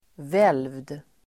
Uttal: [vel:vd]